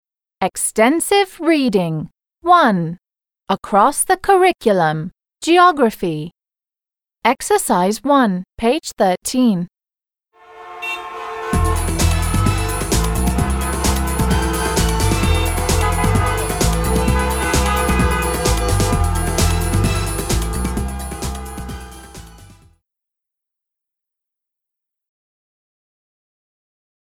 1. Послушайте звуки и посмотрите на картинки.
I think it’s a noisy town with crowded streets and hard traffic.